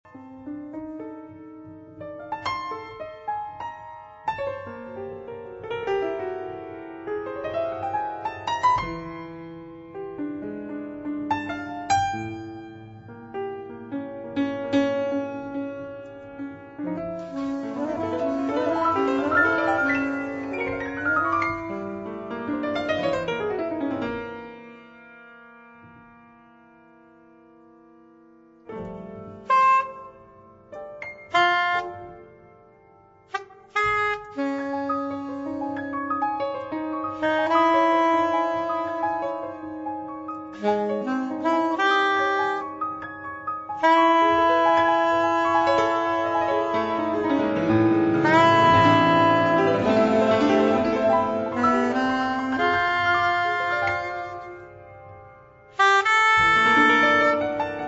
piano
soprano saxophone